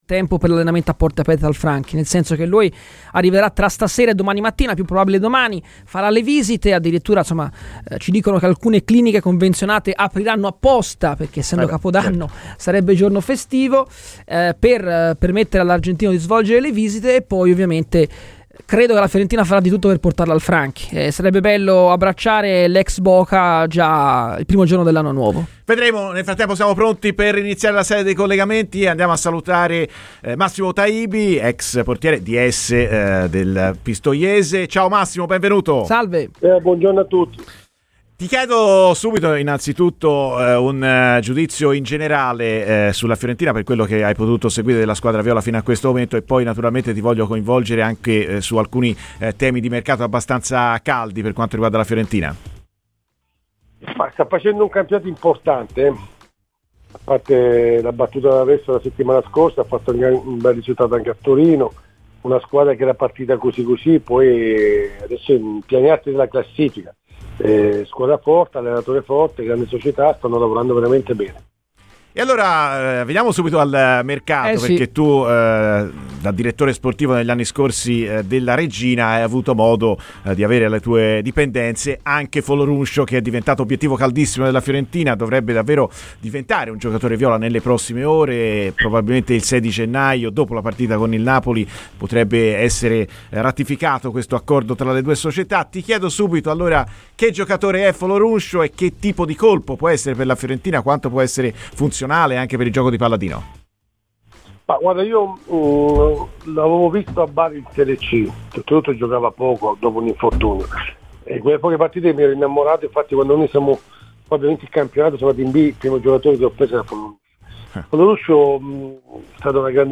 Massimo Taibi, ex portiere nonché attuale ds della Pistoiese, è intervenuto su Radio FirenzeViola nel corso di "Viola Amore Mio" per parlare di Fiorentina.